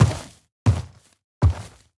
Minecraft Version Minecraft Version snapshot Latest Release | Latest Snapshot snapshot / assets / minecraft / sounds / mob / sniffer / step6.ogg Compare With Compare With Latest Release | Latest Snapshot
step6.ogg